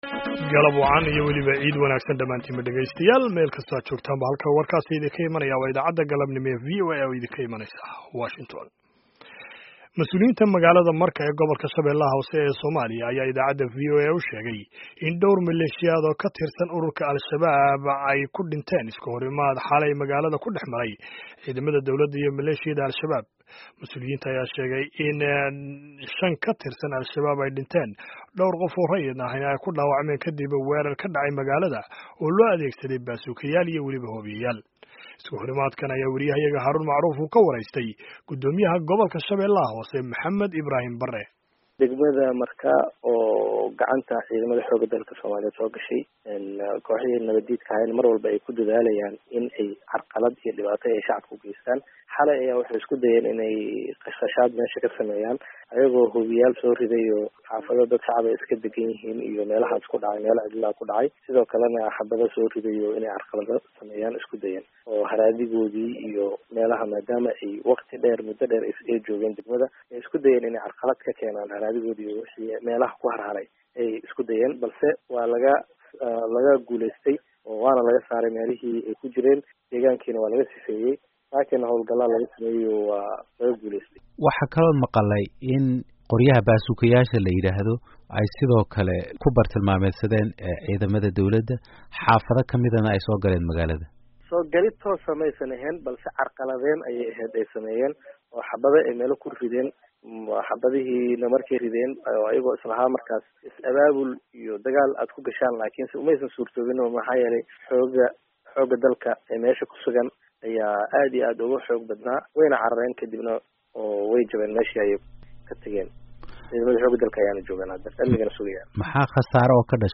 Dhagayso Waraysiga Guddomiyaha Shabeelaha Hoose